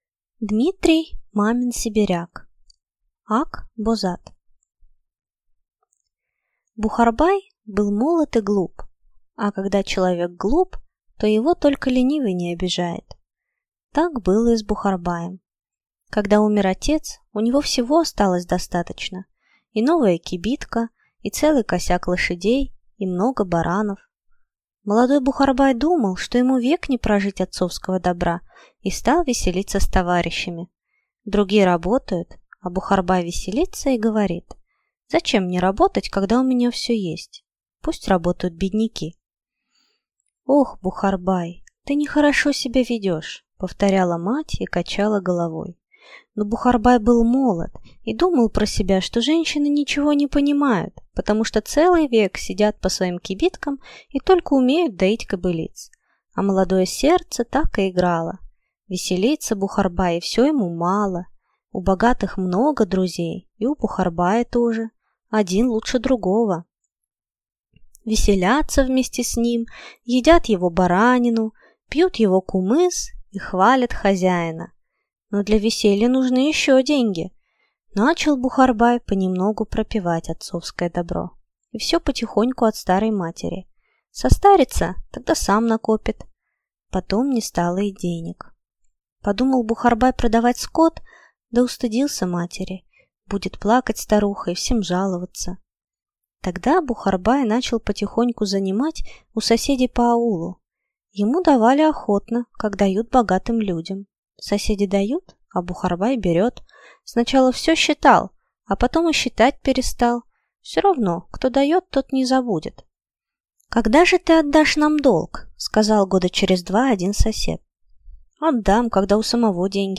Аудиокнига Ак-Бозат | Библиотека аудиокниг